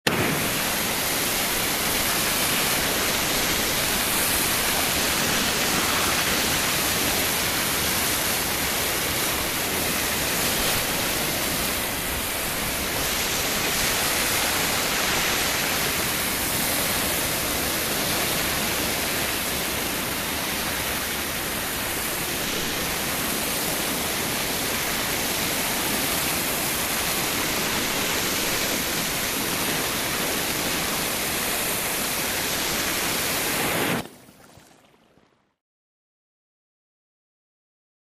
FireHoseTurningOn PE315101
Fire Hose Turning On, Spraying, And Turning Off